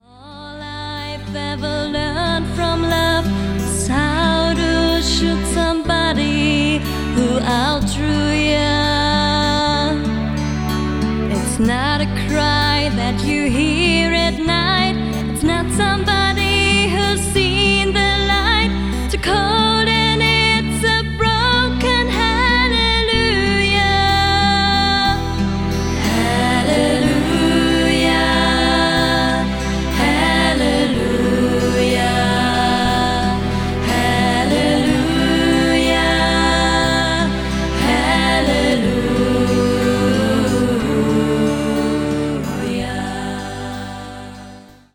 Genre: NGL.